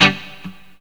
RIFFGTR 19-R.wav